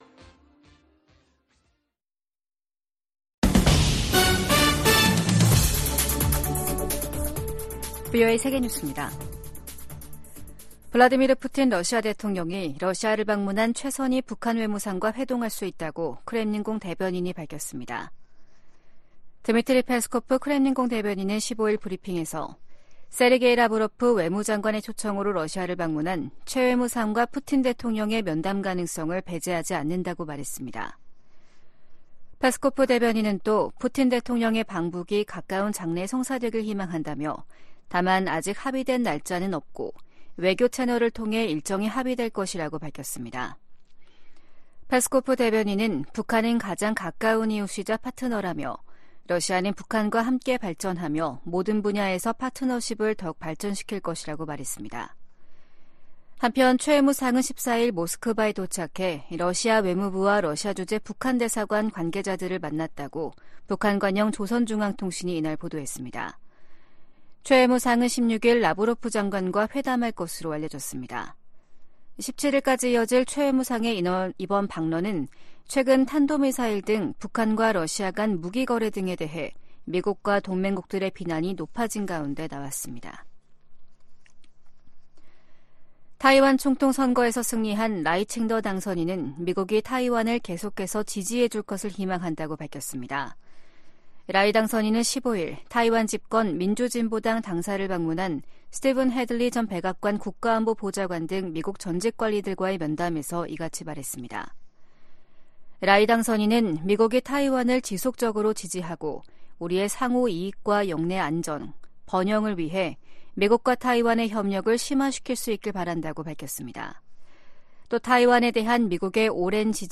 VOA 한국어 아침 뉴스 프로그램 '워싱턴 뉴스 광장' 2024년 1월 16일 방송입니다. 북한은 신형 고체연료 추진체를 사용한 극초음속 중장거리 탄도미사일(IRBM) 시험 발사에 성공했다고 발표했습니다. 미국은 북한의 새해 첫 탄도미사일 발사를 유엔 안보리 결의 위반이라며 대화에 나설 것을 거듭 촉구했습니다. 중국 선박이 또다시 북한 선박으로 국제기구에 등록됐습니다.